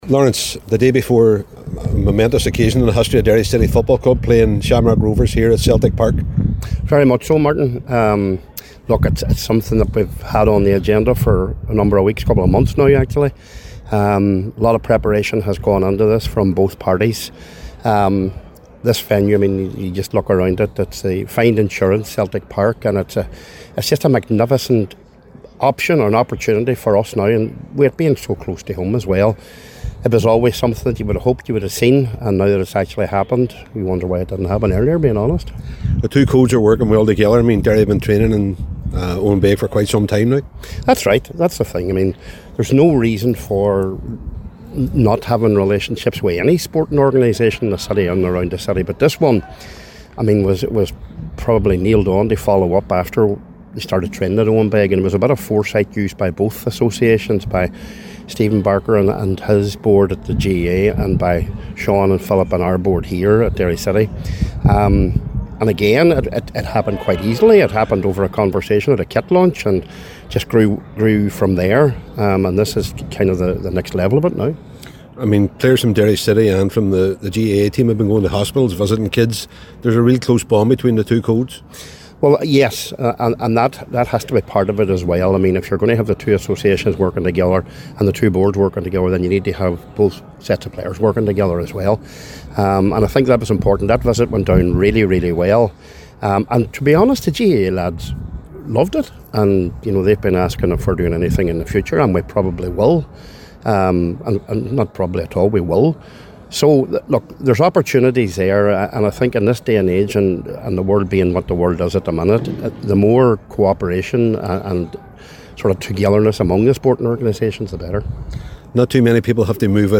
a press event in the lead up to the game